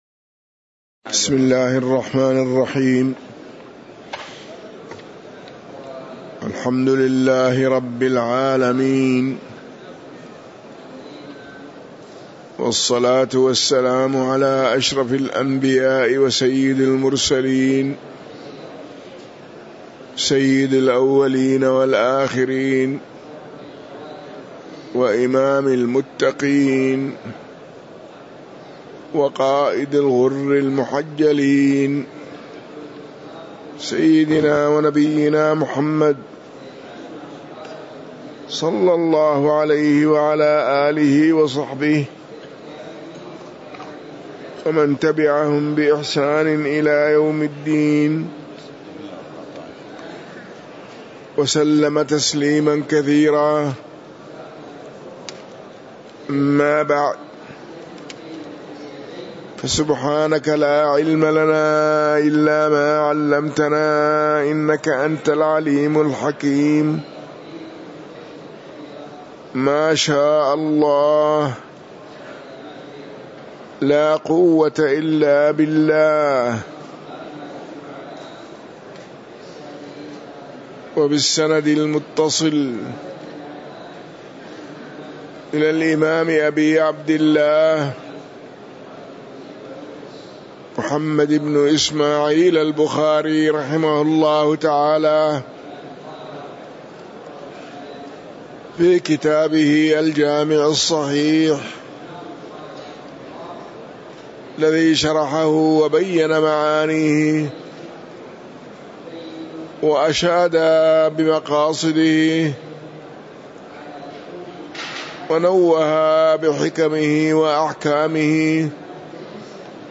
تاريخ النشر ٢١ رجب ١٤٤٤ هـ المكان: المسجد النبوي الشيخ